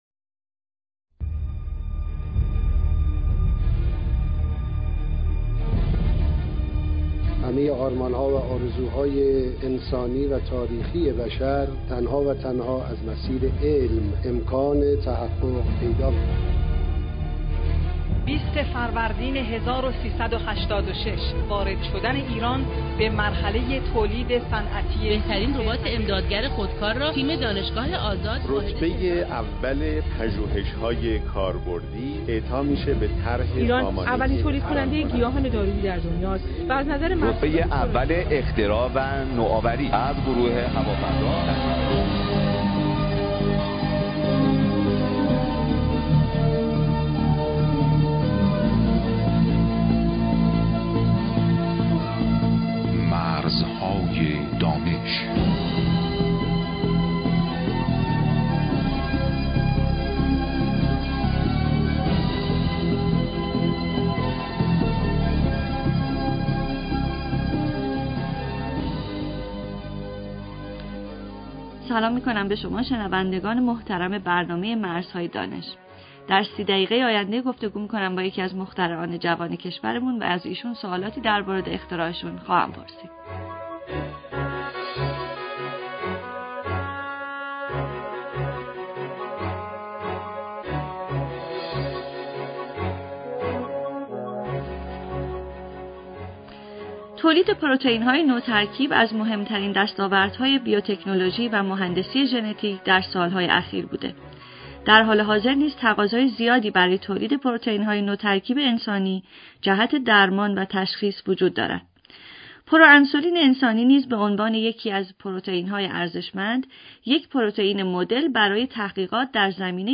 کسانی که مایلند راهکارهای رسیدن به اختراع را به صورت صوتی بشنوند می توانند هر هفته برنامه مرزهای دانش که از رادیو گفتگو پخش می شود دنبال نمایند همچنین در سایت اینترنتی موسسه رشد خلاقیت نوآوران جوان این برنامه ها برای دانلود قرار می گیرد